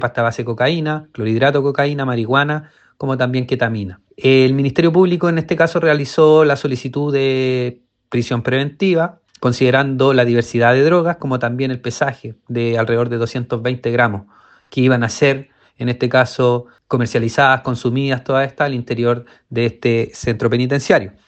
El fiscal (s), Alejandro López, indicó que solicitaron la medida cautelar de prisión preventiva.